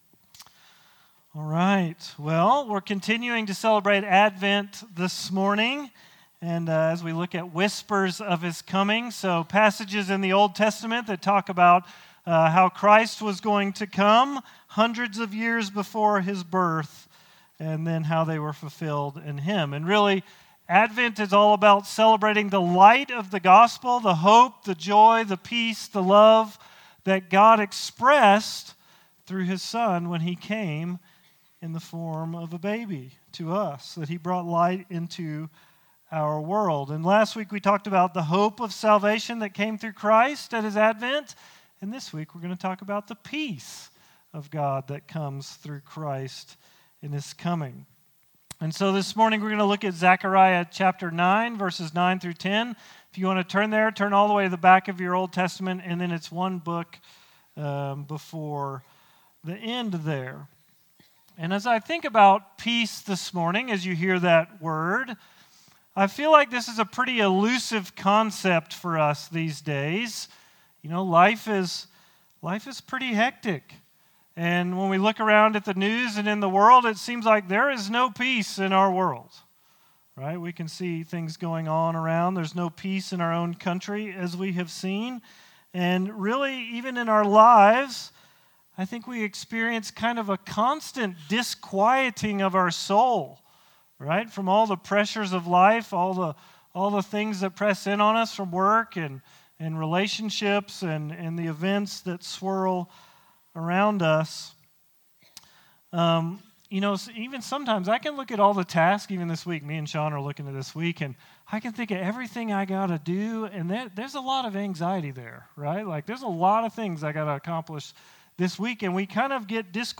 We continue our Advent preaching series looking at Joy from Isaiah 9:1-7.